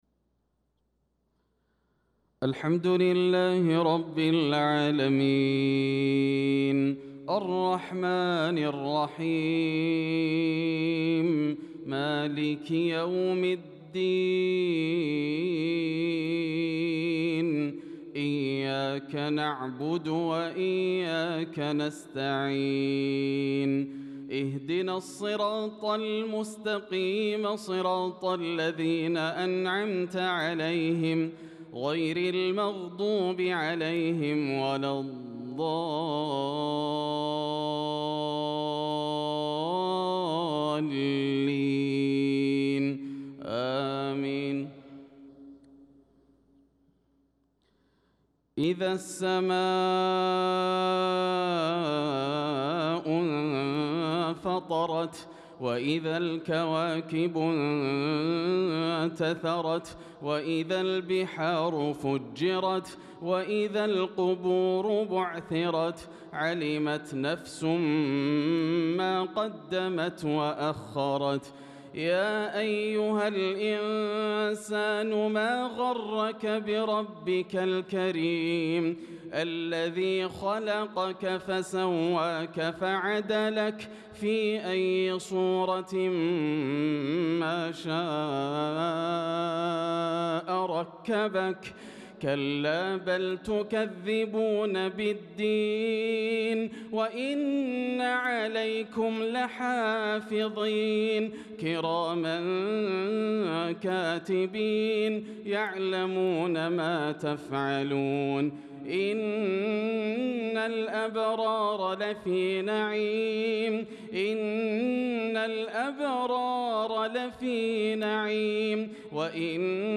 صلاة العشاء للقارئ ياسر الدوسري 25 ذو القعدة 1445 هـ
تِلَاوَات الْحَرَمَيْن .